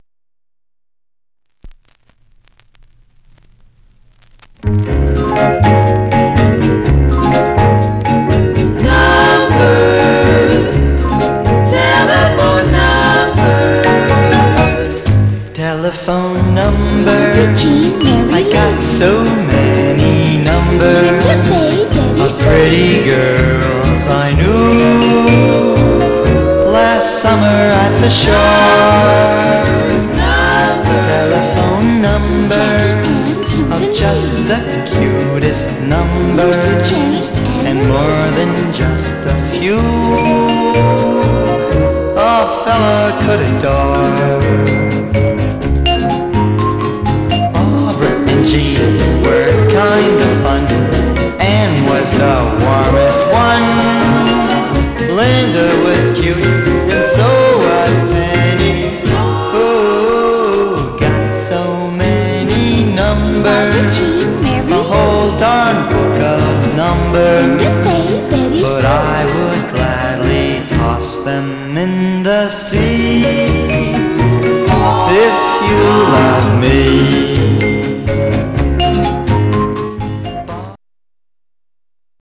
Stereo/mono Mono
Teen